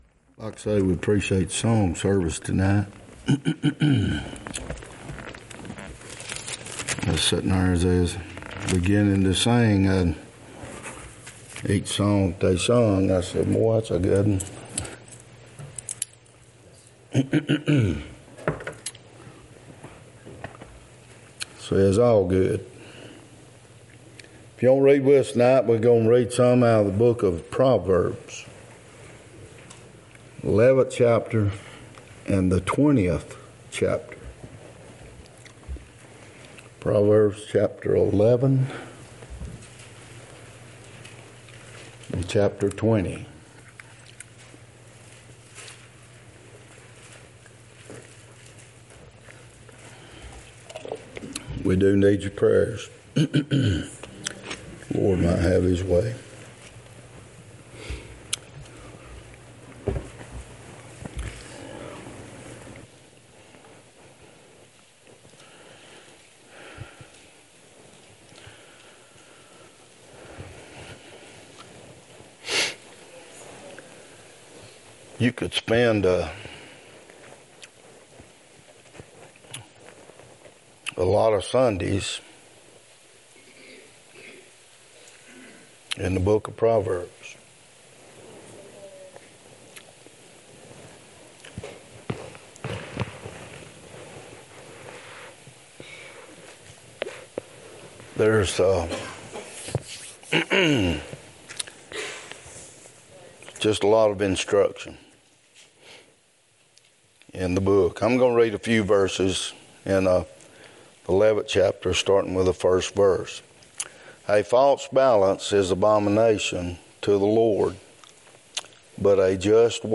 20:20-30 Service Type: Wednesday night Topics